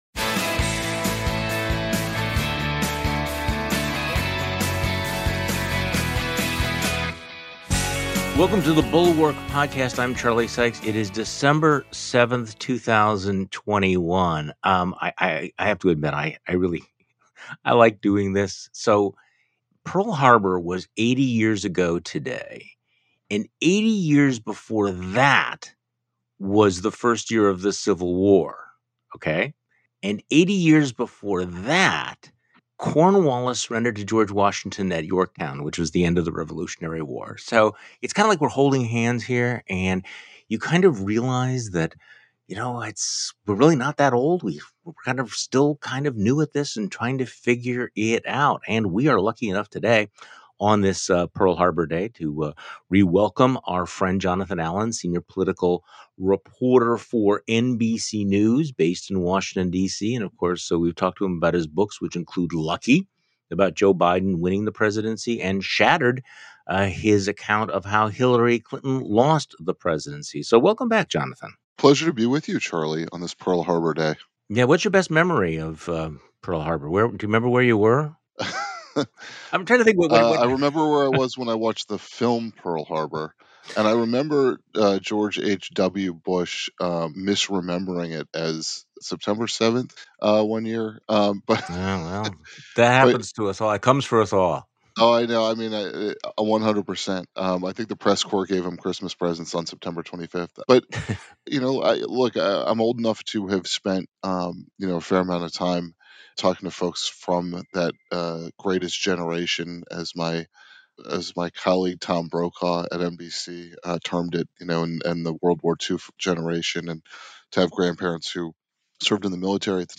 Stacey Abrams sees good odds in the Georgia's governor's race, while the Republican candidates are stuck in the amber of Trump's Big Lie. Plus: Nunes' job change shows that being on Fox is the new path to power. NBC News' Jonathan Allen joins Charlie Sykes on today's podcast.